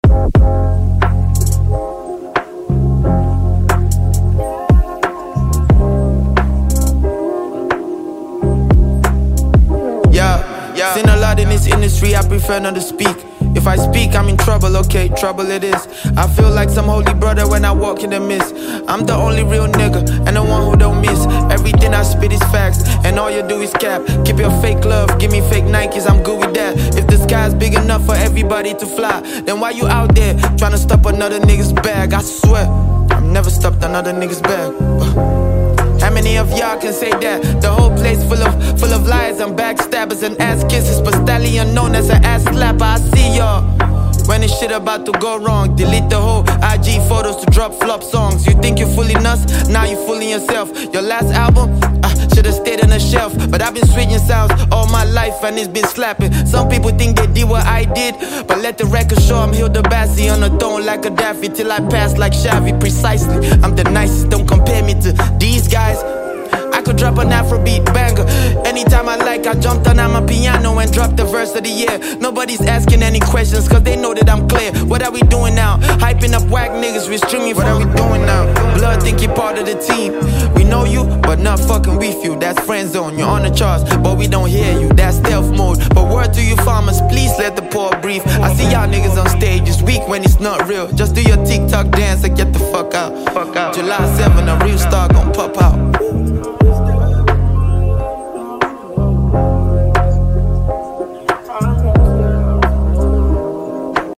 With his unique style and charismatic delivery